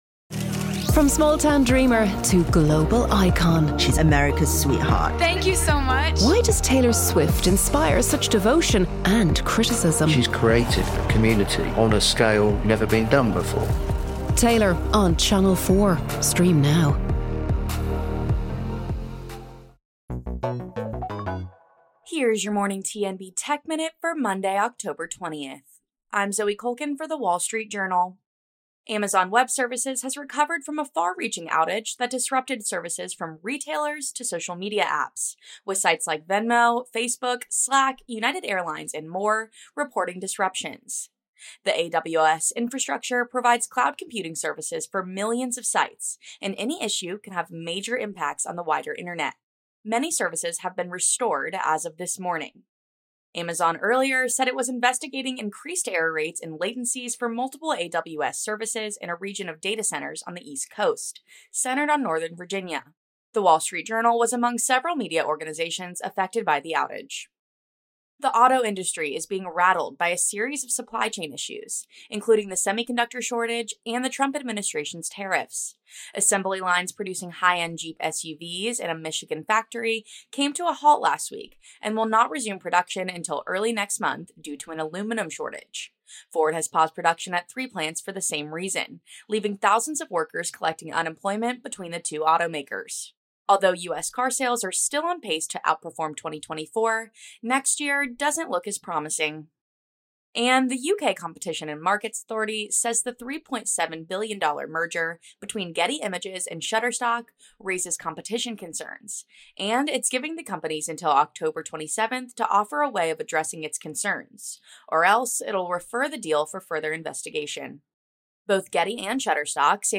⬜ Welcome to Palvatar Market Recap, your go-to daily briefing on the latest market movements, global macro shifts, and crypto trends—powered by Raoul Pal’s AI avatar, Palvatar.